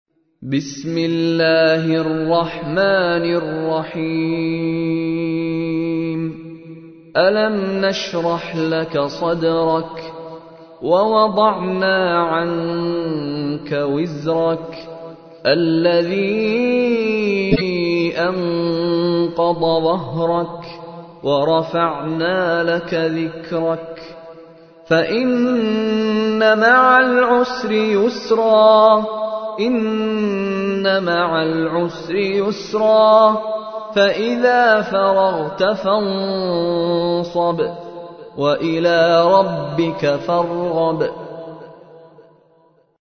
تحميل : 94. سورة الشرح / القارئ مشاري راشد العفاسي / القرآن الكريم / موقع يا حسين